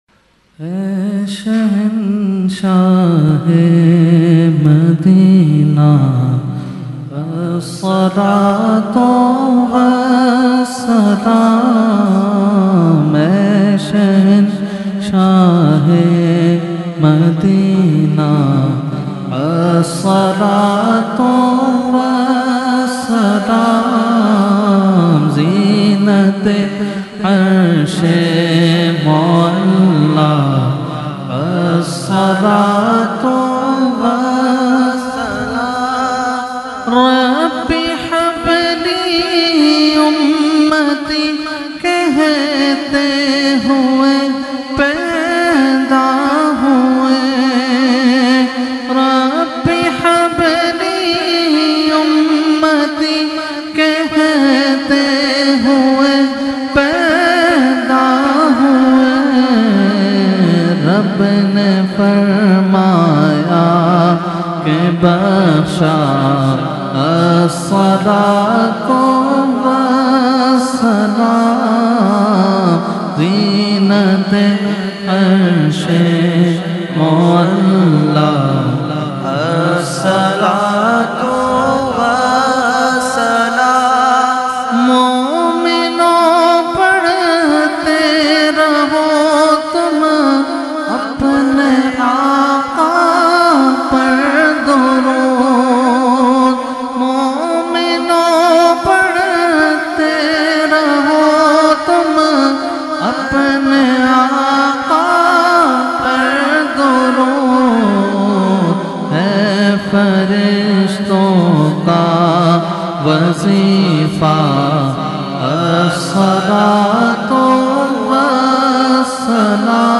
9 Roza Mehfil e Muharram ul Haram held on 1st Muharram ul Haram to 9th Muharram ul Haram at Jamia Masjid Ameer Hamza Nazimabad Karachi.
Category : Salam | Language : UrduEvent : Muharram 2021